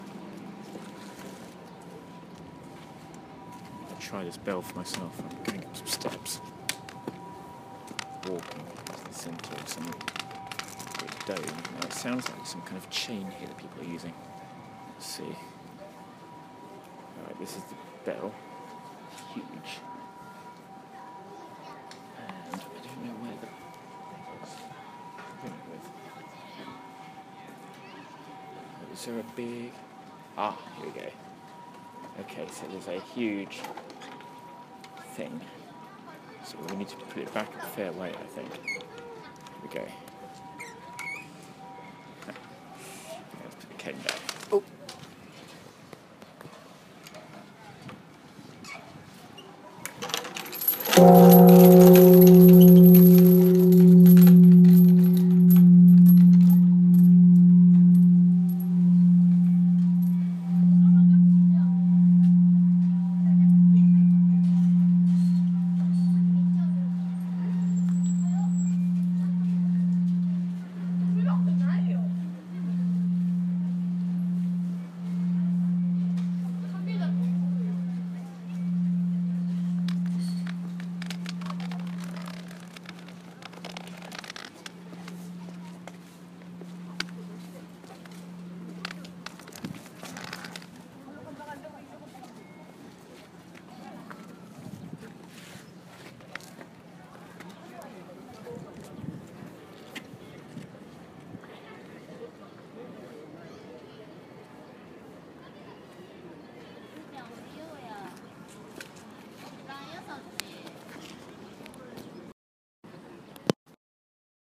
I ring the Bell of Peace in Hiroshima, Japan.